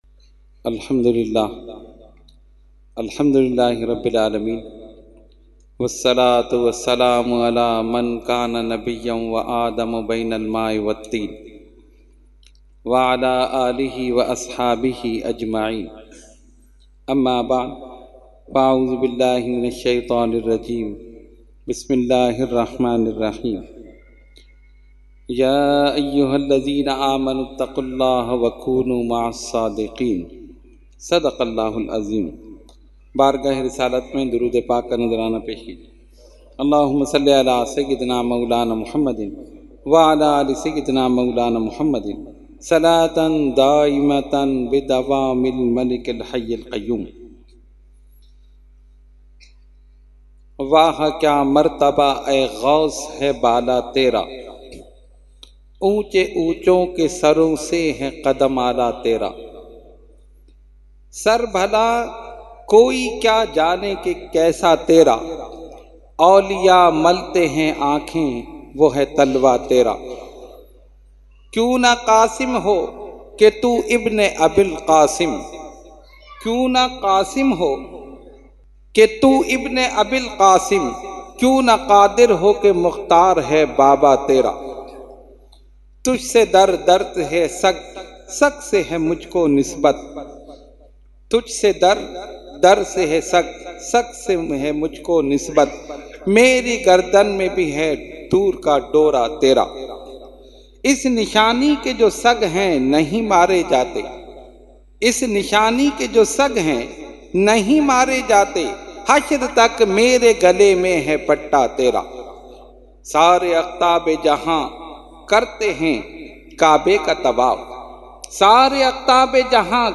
Category : Speech | Language : UrduEvent : 11veen Shareef 2019